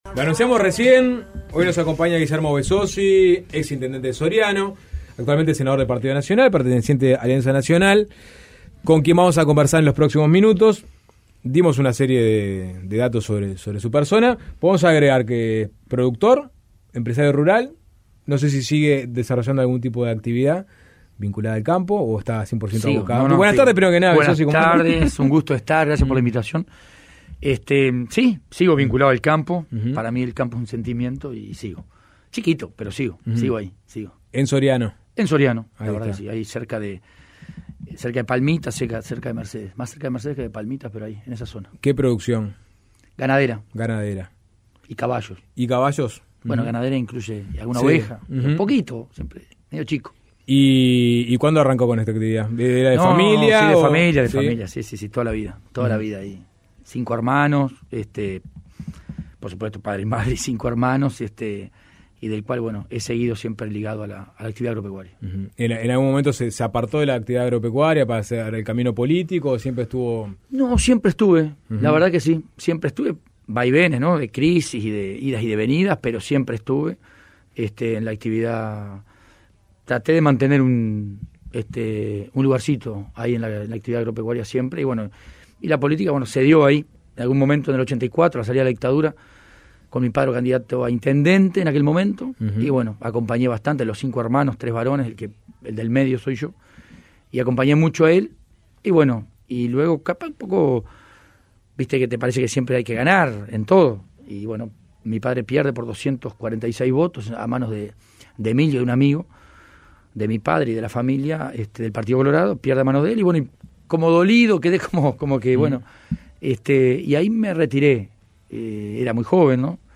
Entrevistamos al senador nacionalista Guillermo Besozzi, quien habló sobre la interna de Alianza Nacional y el liderazgo de Jorge Larrañaga. Además, Besozzi se pronunció sobre distintas estrategias para combatir la inseguridad.
Entrevista completa